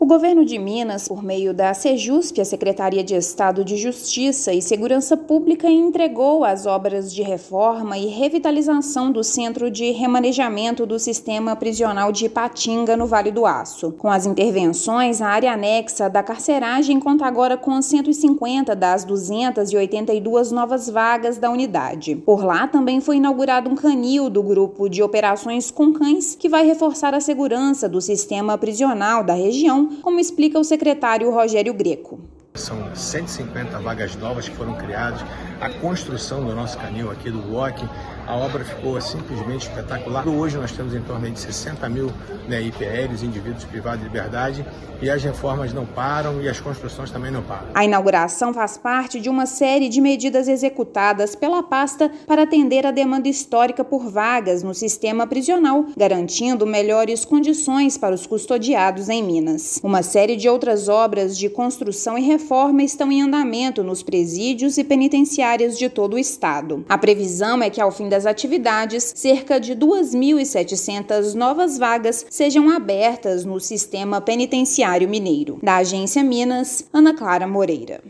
Reformas ampliam em 153% a capacidade da unidade e fazem parte de um grande pacote de entregas do Governo de Minas para o sistema prisional. Ouça matéria de rádio.